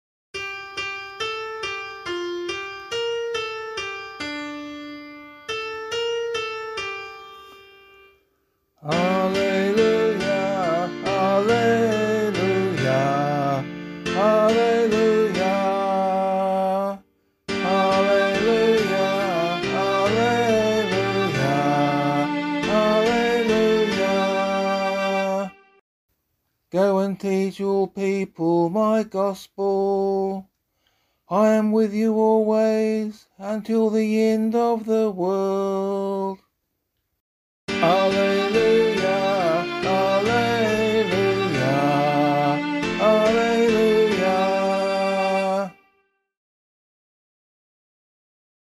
Gospel Acclamation for Australian Catholic liturgy.